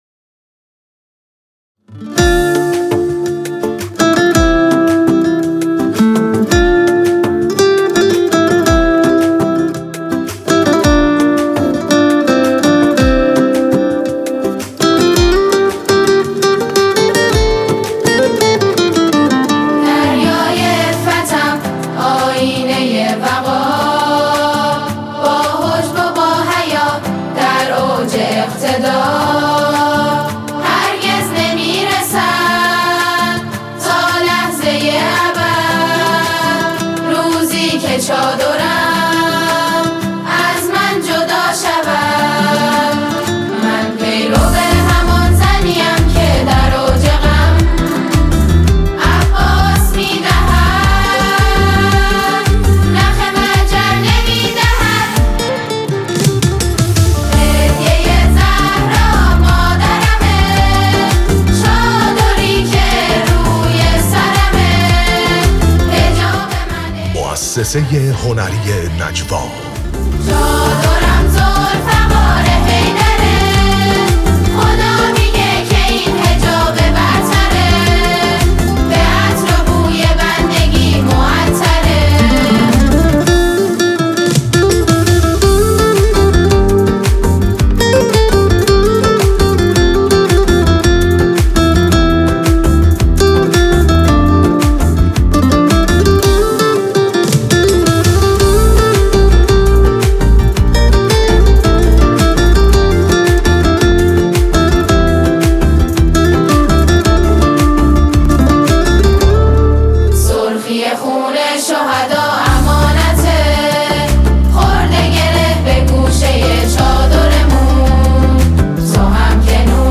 با اجرای گروه سرود نغمه آسمان